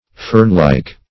fernlike - definition of fernlike - synonyms, pronunciation, spelling from Free Dictionary